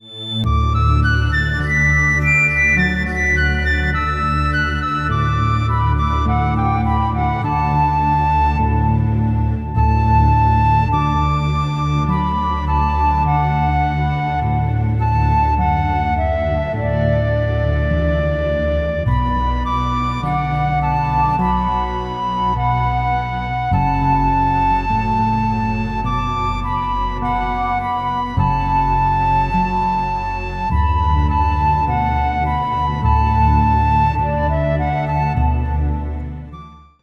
8:54 -52 bpm
12-string LucyTuned guitar